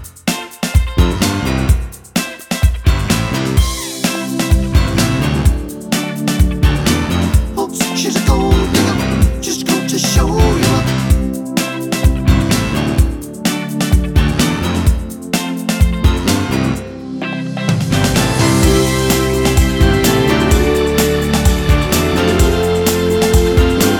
Two Semitones Down Pop (2000s) 3:42 Buy £1.50